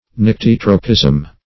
Search Result for " nyctitropism" : The Collaborative International Dictionary of English v.0.48: Nyctitropism \Nyc*tit"ro*pism\, n. [From Gr. ny`x, nykto`s, night + ? to turn.]